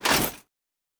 Foley Armour 09.wav